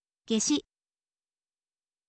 geshi